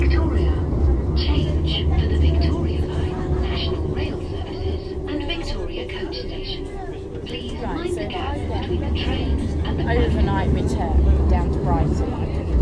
The tube annoucer on the district line sounds like the voice over on masterchef